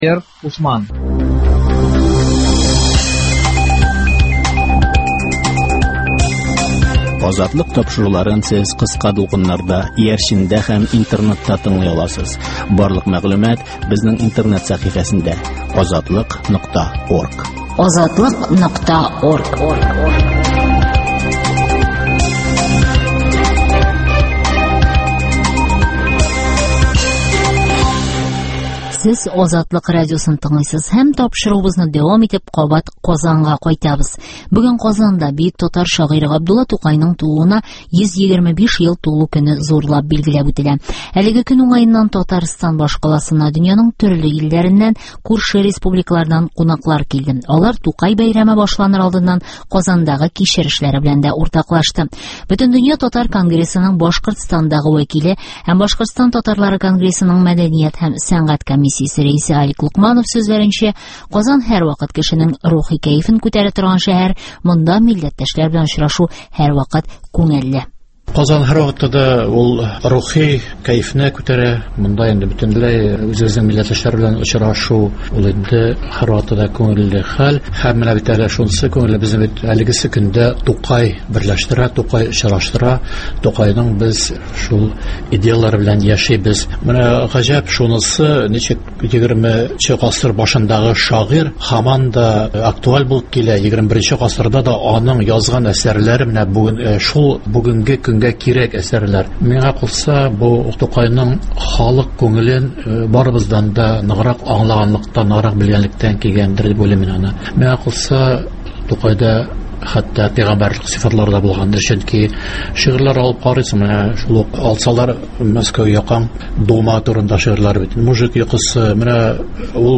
Туган тел көненнән репортаж (2)